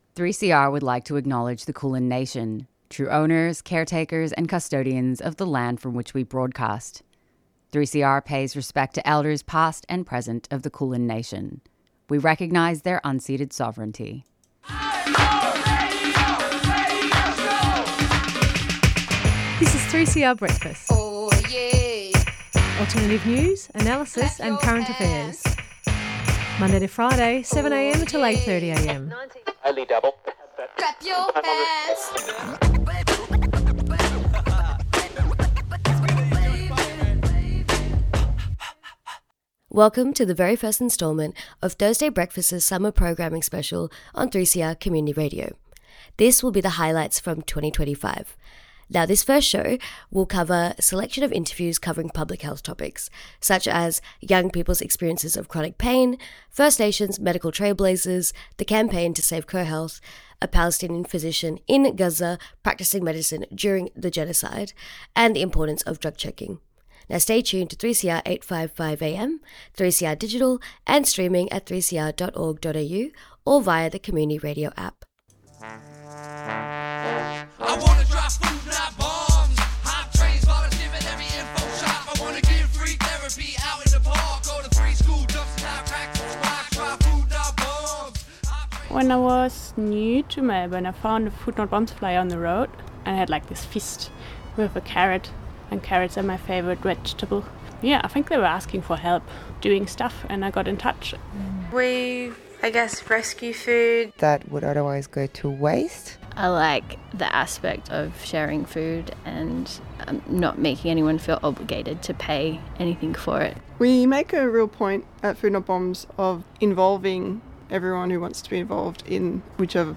Tweet Thursday Breakfast Thursday 7:00am to 8:30am Current affairs, media analysis, alternative media.